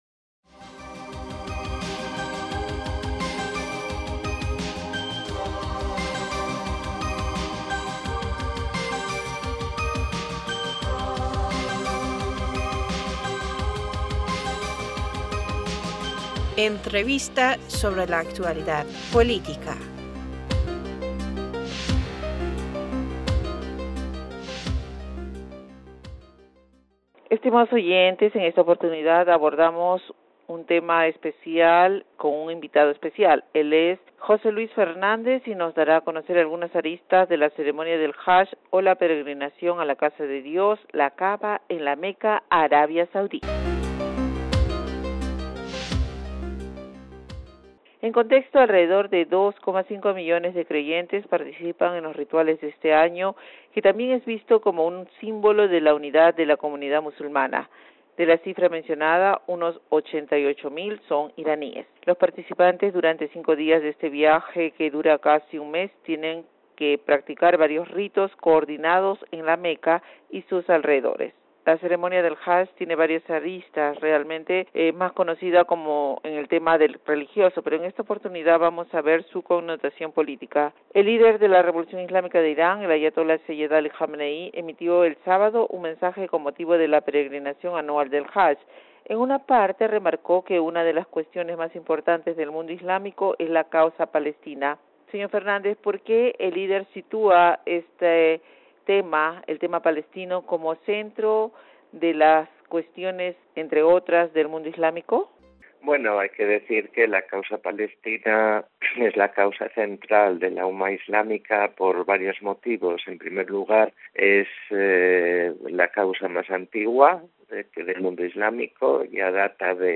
ParsToday- Entrevistador (E): Estimados oyentes, en esta oportunidad abordamos y tema especial con un invitado especial.